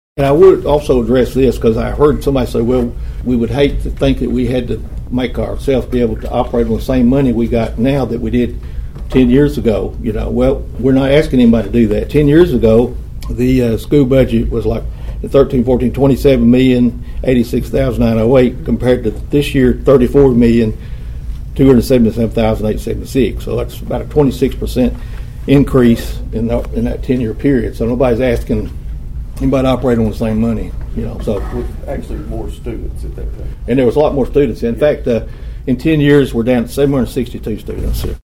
During his nearly 17-minute address to the Budget Committee last week, Mayor Carr said County Commissioners have exceeded their maintenance of effort for the schools.(AUDIO)